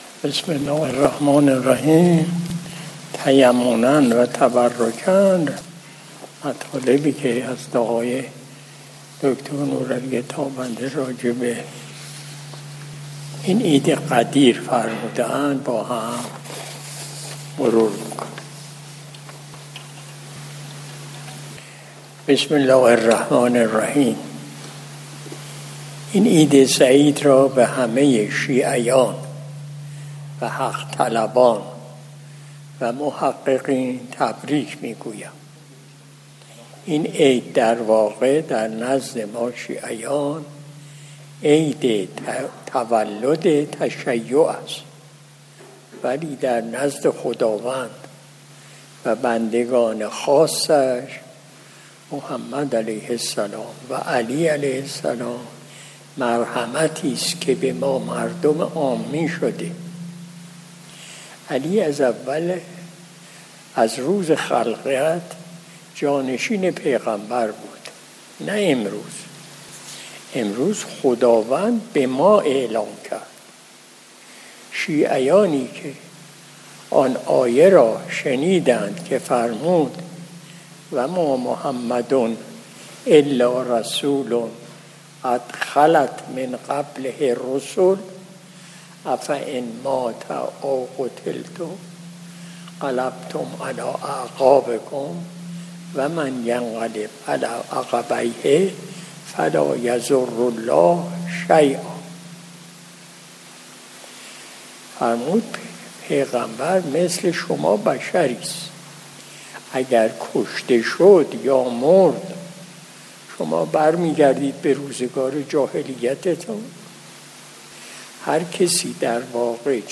قرائت متن دو فرمایش از حضرت آقای حاج دکتر نورعلی تابنده مجذوبعلیشاه طاب‌ثراه در باب «عید سعید غدیر خم»
مجلس شب جمعه ۱۵ تیر ماه ۱۴۰۲ شمسی